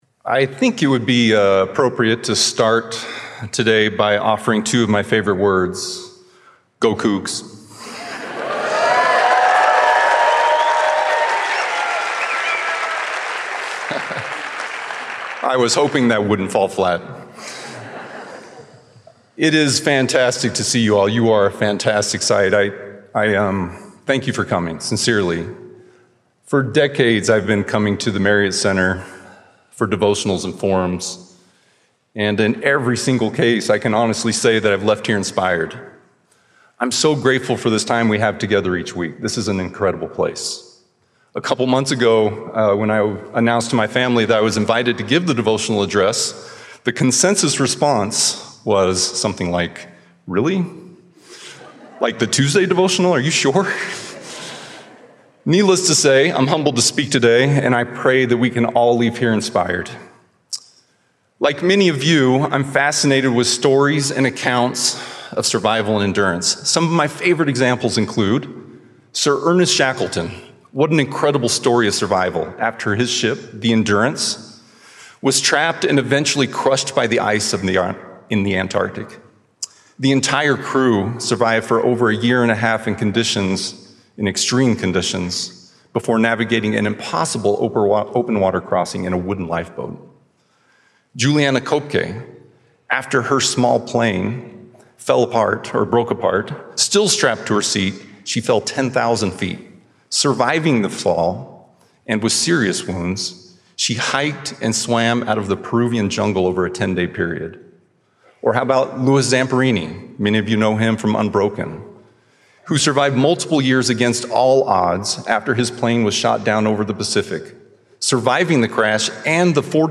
2024 MP3 PDF In the race of life, we can be strengthened by repenting continually, anticipating adversity, cleaving to covenants, and enduring to the end. devotional 2024